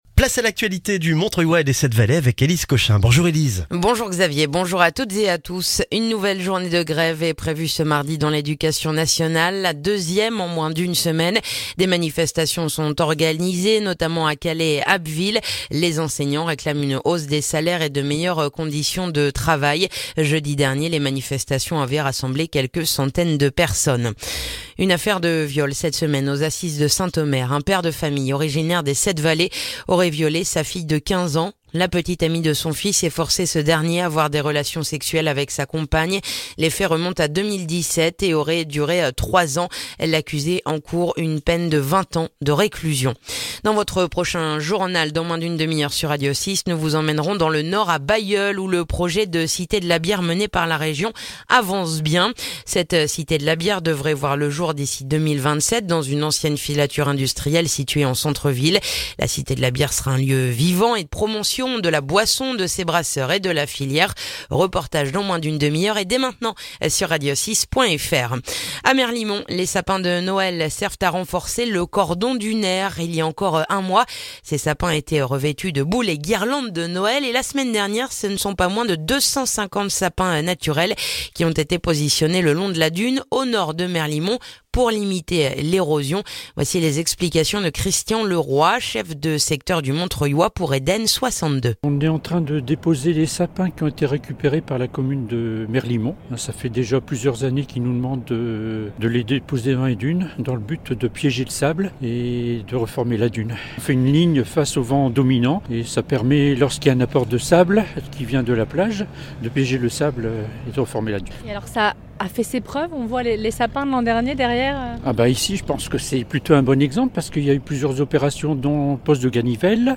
Le journal du mardi 6 février dans le montreuillois